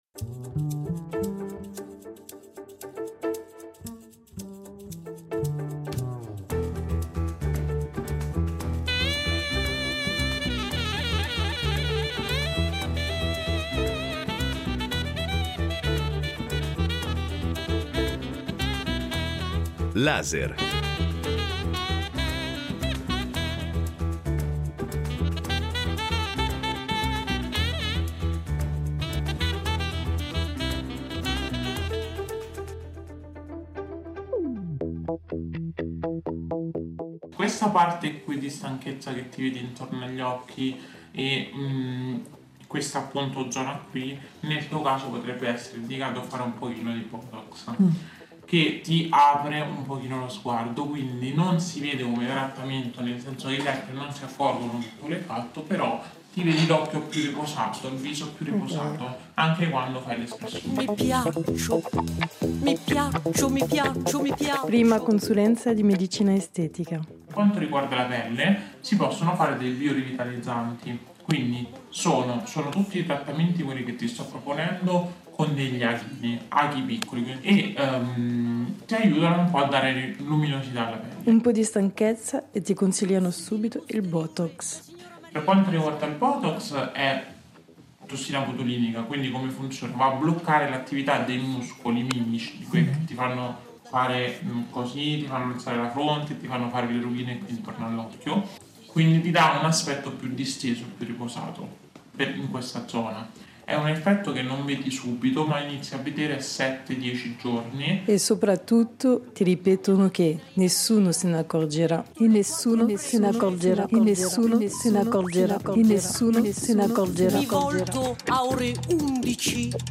Un audiodocumentario